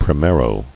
[pri·me·ro]